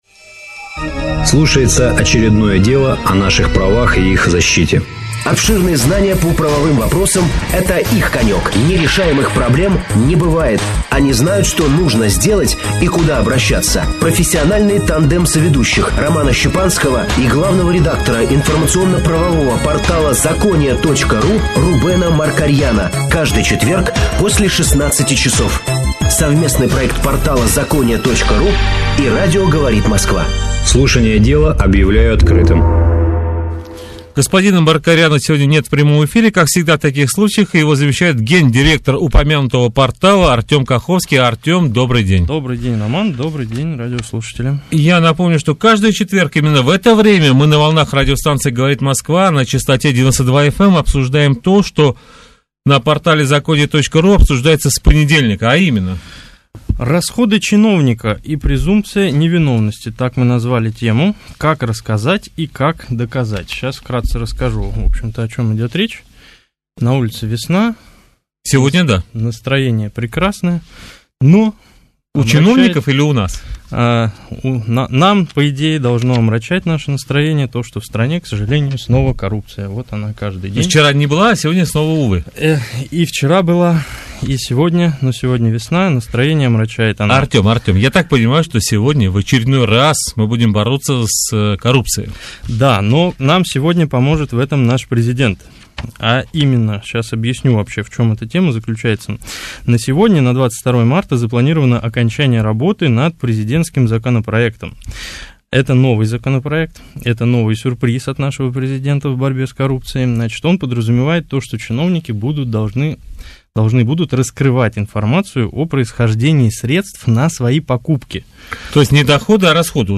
Совместный проект портала «ЗАКОНИЯ» и радио «Говорит Москва».